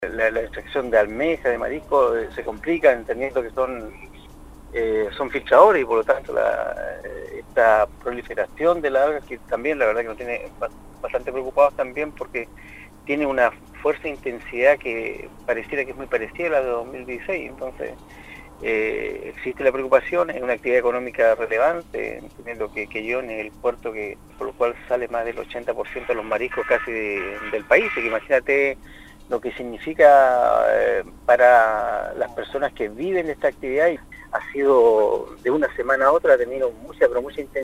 En conversación con el programa Primera Hora de Radio Sago, Garcés mostró preocupación por la intensidad de la floración algal que ha permitido la expansión de la Marea Roja en las costas de Quellón y Queilen.